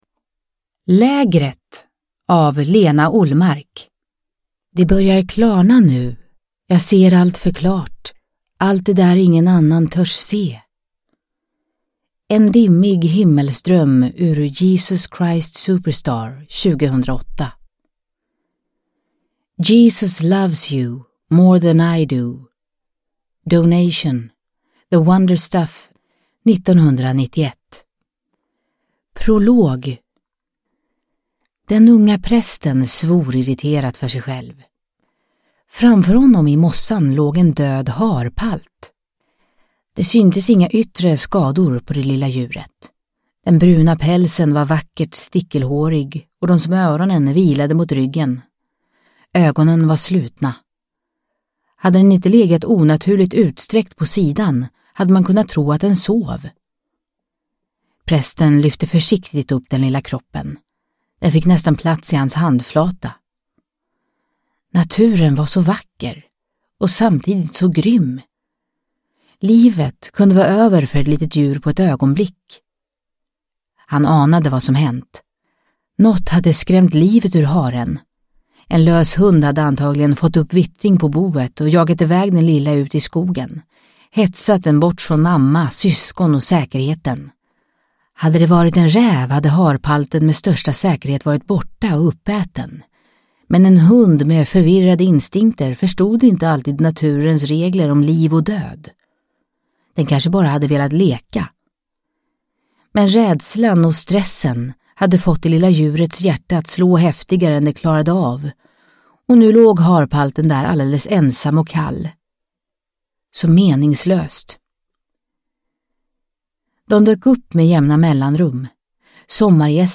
Lägret – Ljudbok – Laddas ner